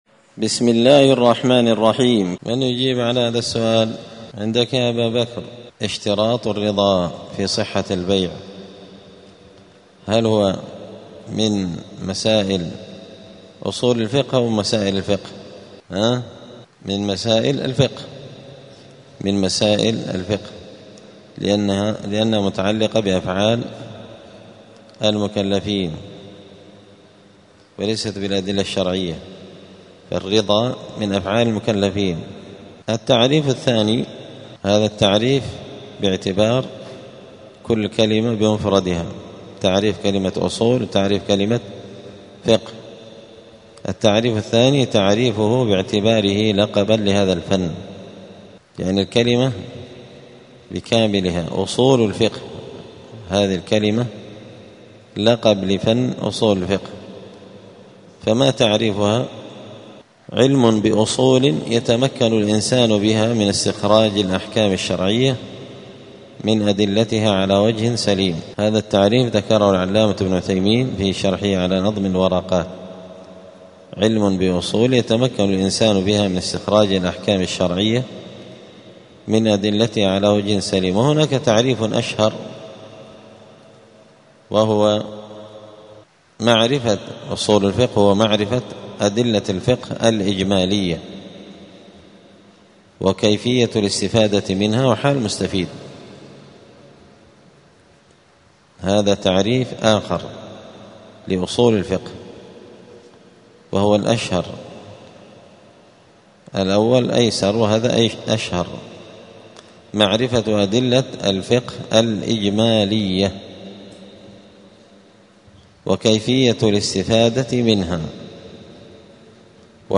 دار الحديث السلفية بمسجد الفرقان قشن المهرة اليمن
الخميس 24 ربيع الثاني 1447 هــــ | الدروس، الفصول للمبتدئين في علم الأصول، دروس الفقة و اصوله | شارك بتعليقك | 8 المشاهدات